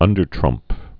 (ŭndər-trŭmp)